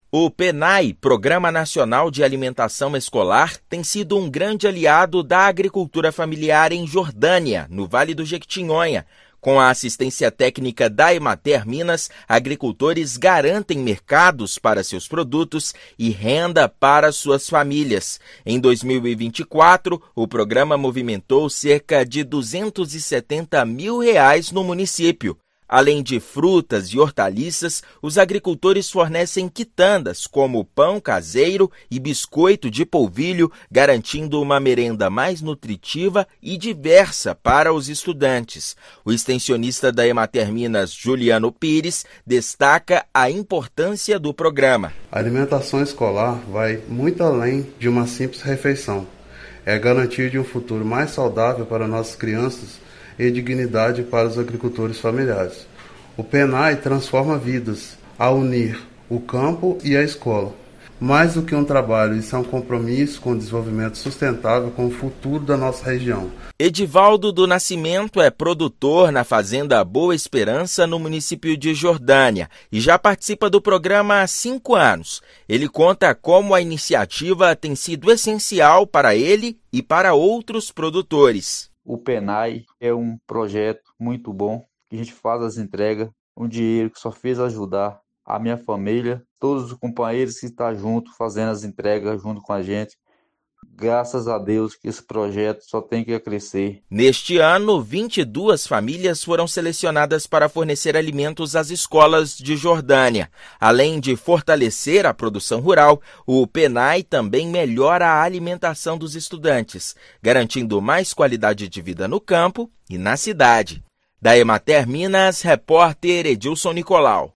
Com comércio de alimentos para escolas da cidade, agricultores têm garantido mercado para seus produtos. Ouça matéria de rádio.